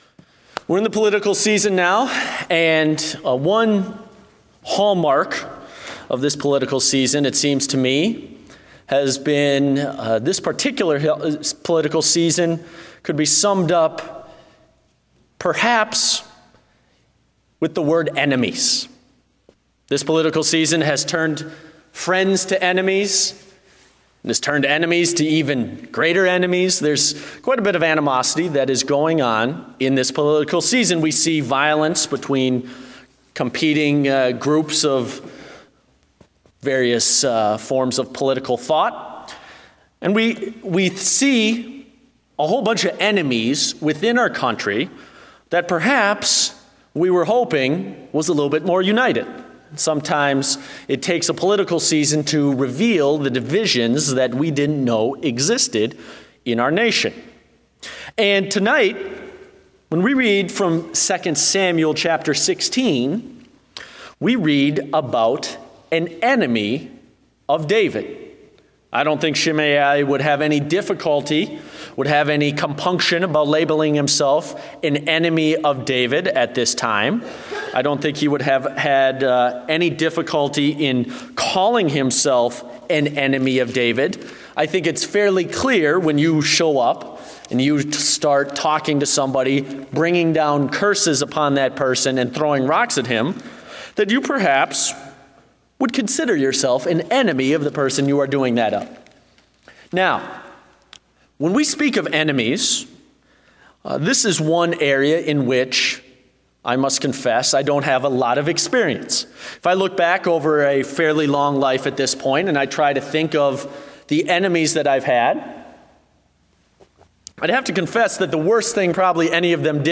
Date: May 1, 2016 (Evening Service)